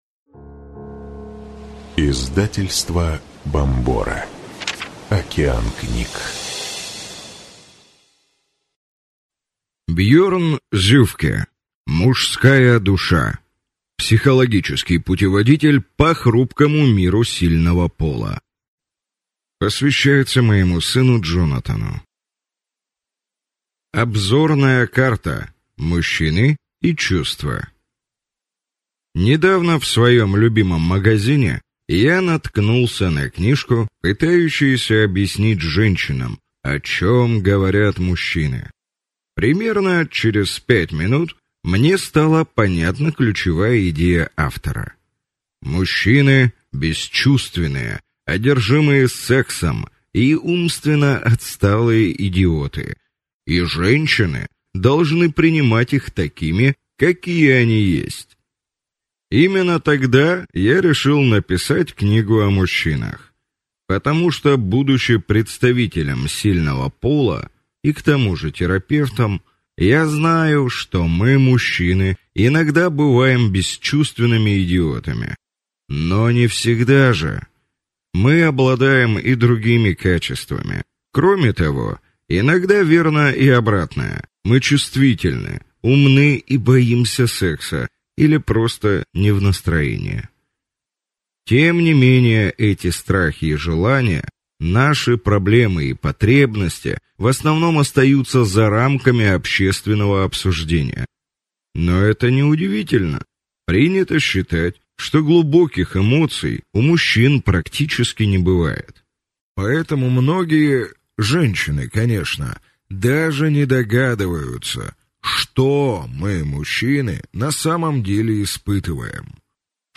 Аудиокнига Мужская душа. Психологический путеводитель по хрупкому миру сильного пола | Библиотека аудиокниг